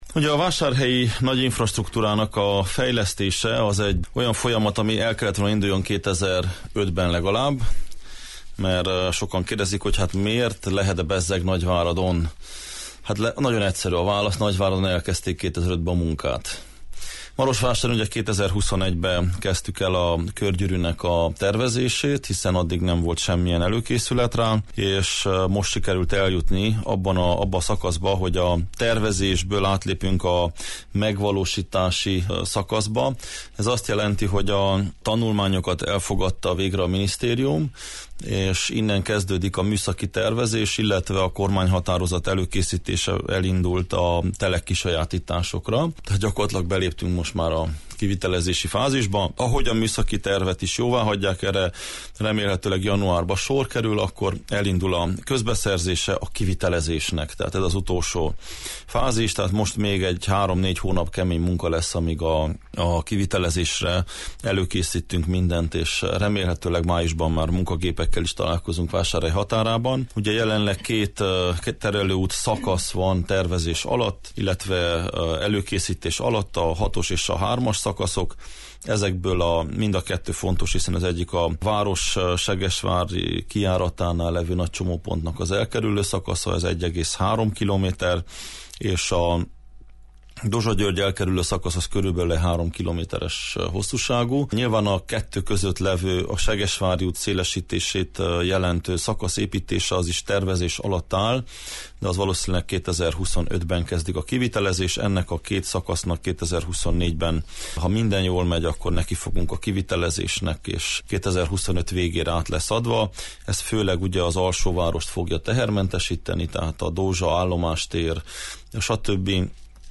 Aktuális kérdésekről beszélgettünk Soós Zoltánnal
A mai Jó reggelt, Erdély!-ben Marosvásárhely polgármestere, Soós Zoltán volt a vendégünk.